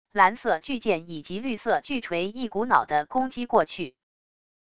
蓝色巨剑以及绿色巨锤一股脑的攻击过去wav音频生成系统WAV Audio Player